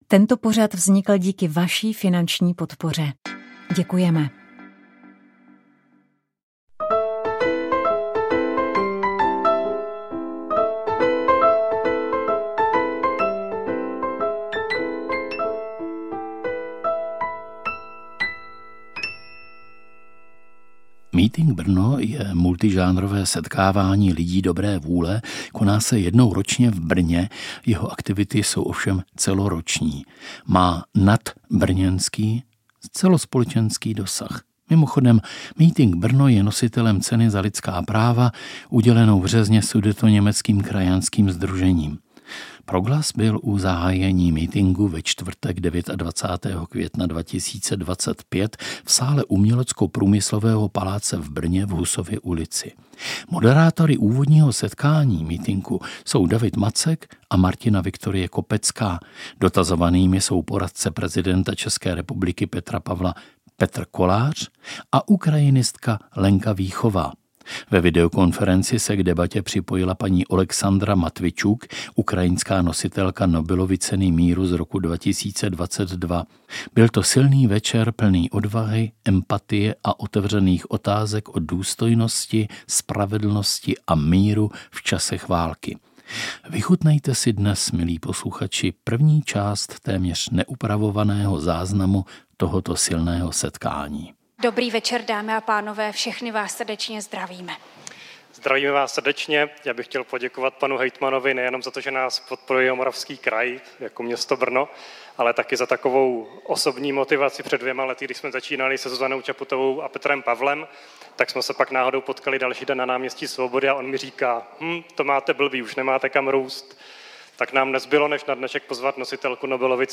Proto jsme pozvali do studia Hradec Králové tři hosty